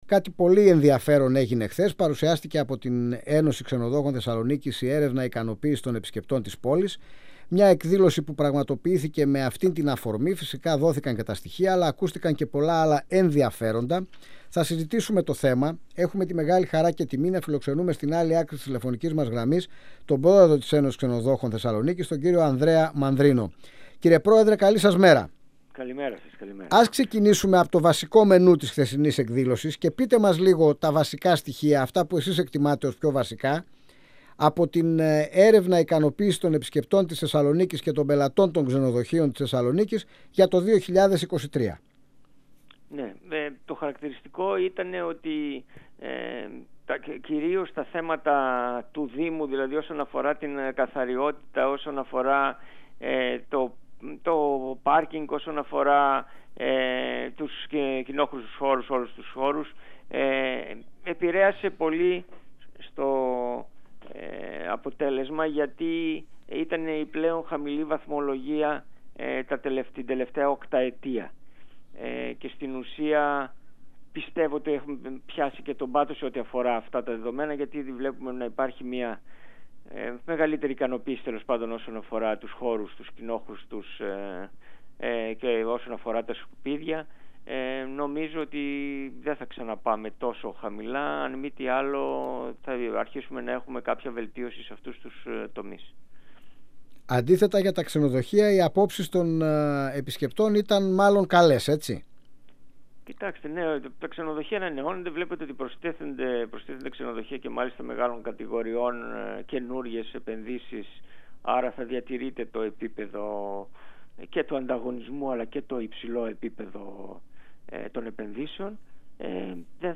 μιλώντας στην εκπομπή «Αίθουσα Σύνταξης» του 102FM της ΕΡΤ3.